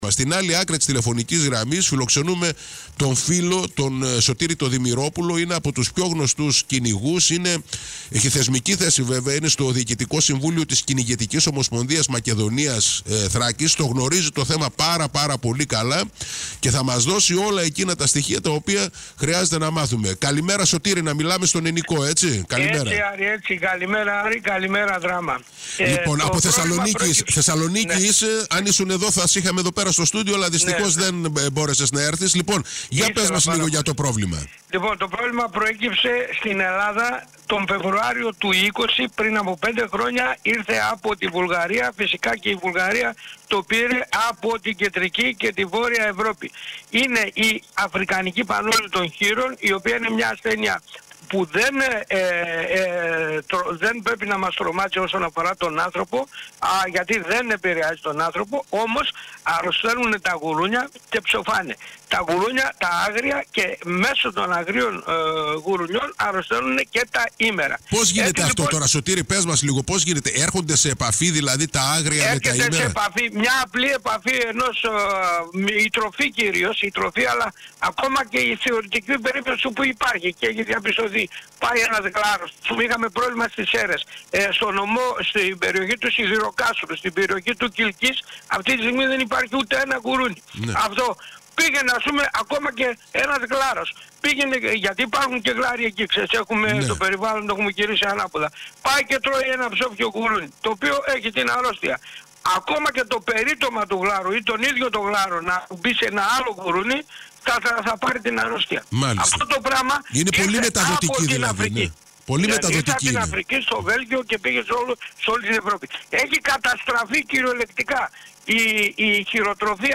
σε συνέντευξη του στον ALPHA NEWS 95,5